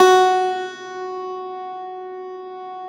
53e-pno12-F2.wav